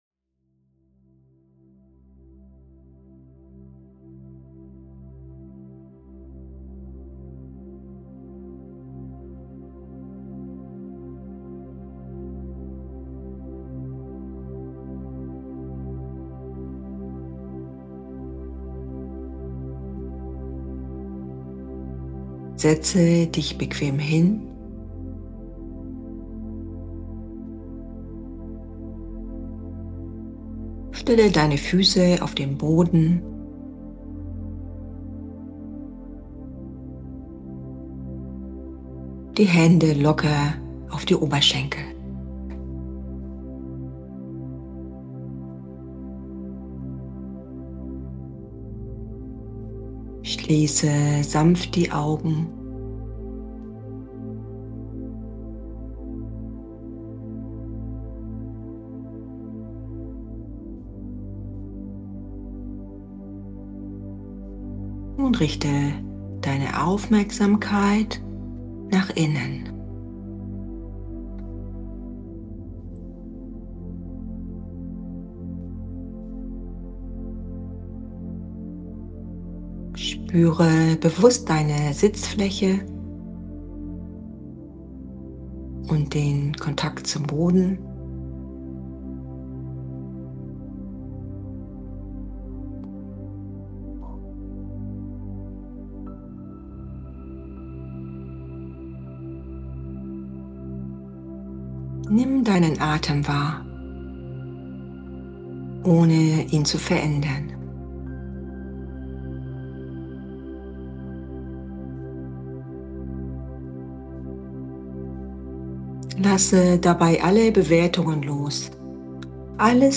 Qigong-Meditation für Ruhe & Energie – perfekt für Anfänger Diese sanfte Meditation ist eine vereinfachte Form der Qigong-Praxis – ideal für absolute Anfänger.